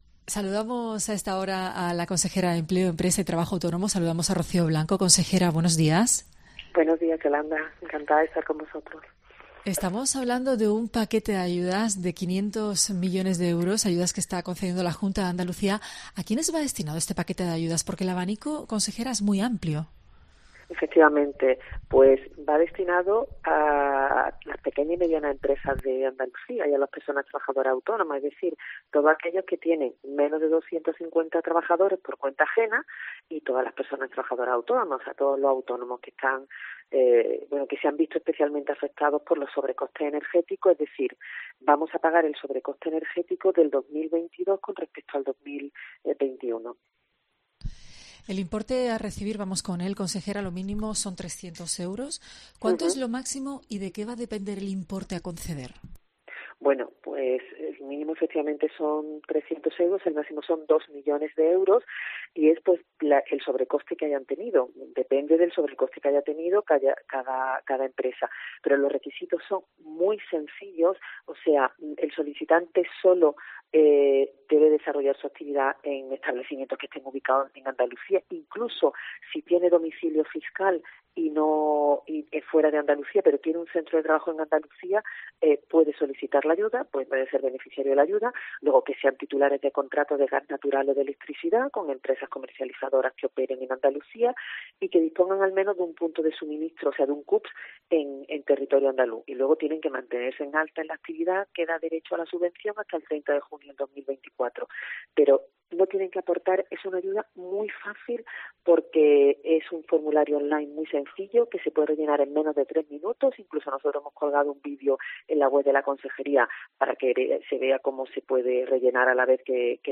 En COPE Andalucía hablamos con la Consejera de Empleo, Empresa y Trabajo Autónomo, Rocío Blanco, sobre estas ayudas para despejar todas las claves. Puedes escuchar la entrevista pinchando aquí o leer todos los detalles a continuación: